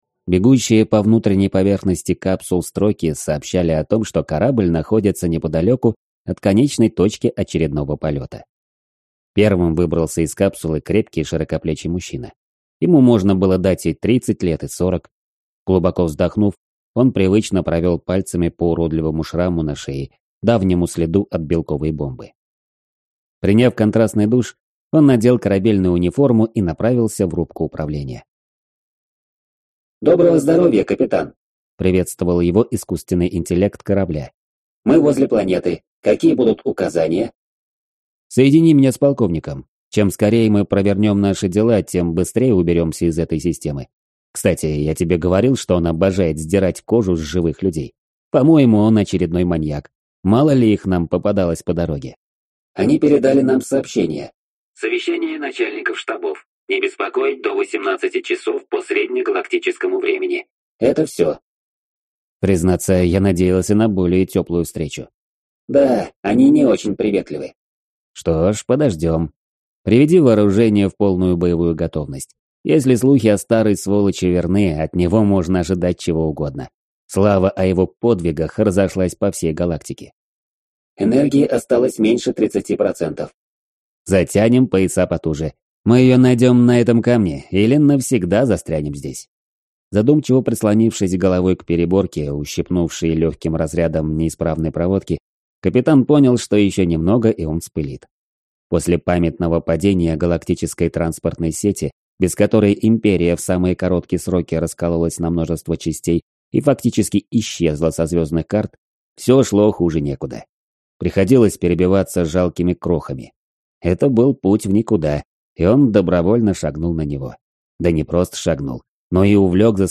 Аудиокнига На краю Вселенной | Библиотека аудиокниг